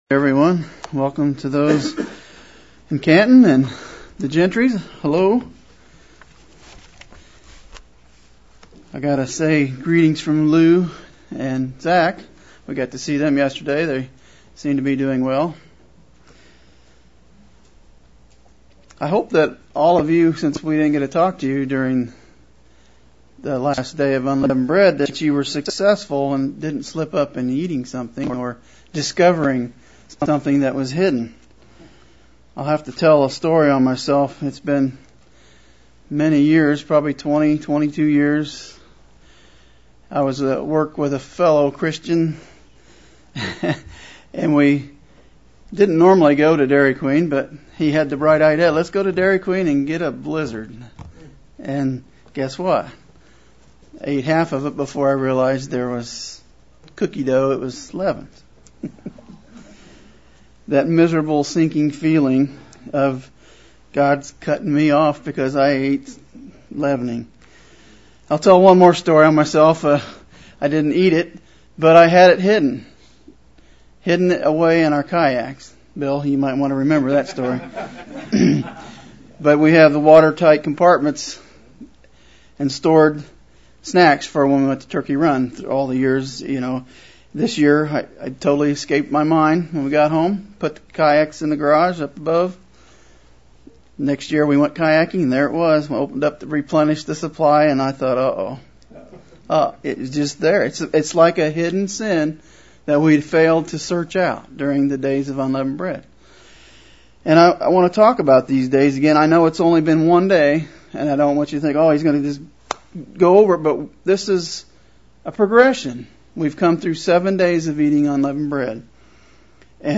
How we can focus spiritually on living an unleavened life each and every day. This sermon was given during the Days of Unleavened Bread.